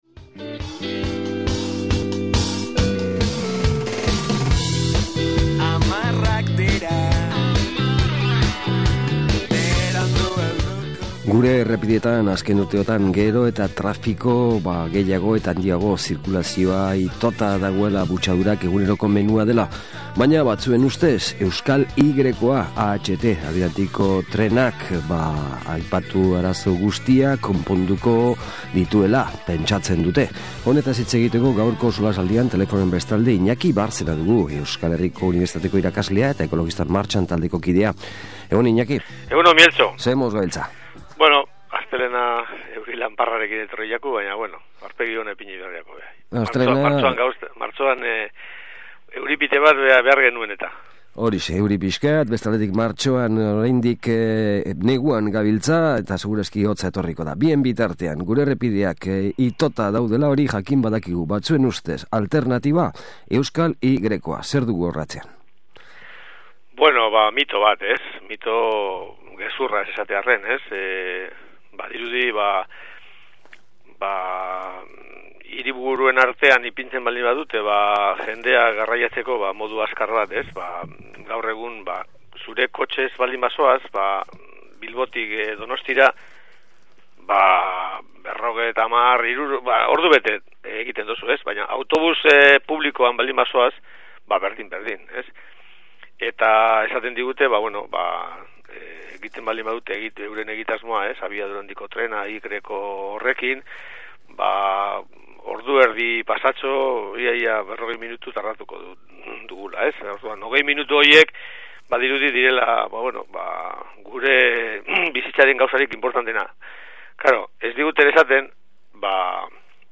SOLASALDIA: AHT eta Errepideak